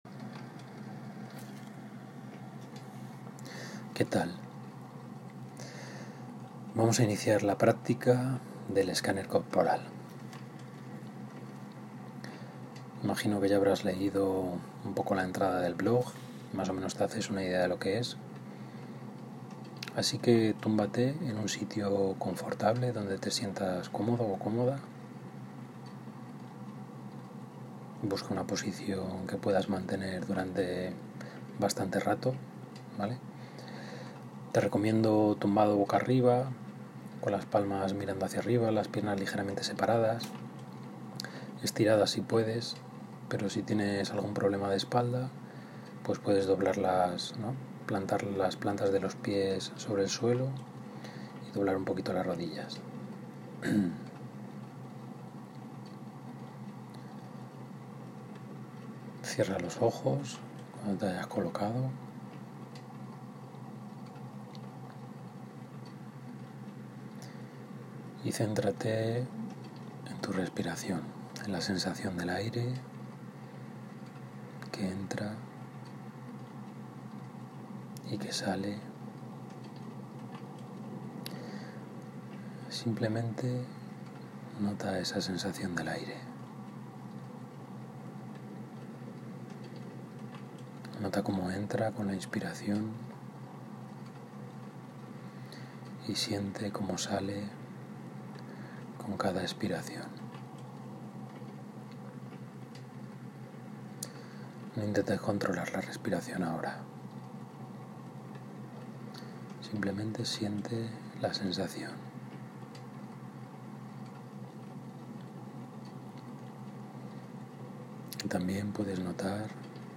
El audio tiene unos cuantos errores.
De hecho, puse de fondo ruido de tormenta y de una chimenea crepitando y parece ruido blanco…
Y por último digo «cuello cabelludo» queriendo decir «cuero cabelludo».